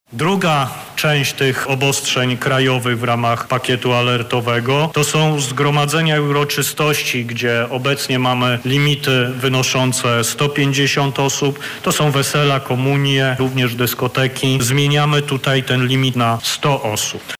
Obostrzenia krajowe będą dotyczyć zmniejszenia obłożenia obiektów publicznych do 50 % – podał minister zdrowia Adam Niedzielski: